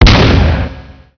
flakfire.wav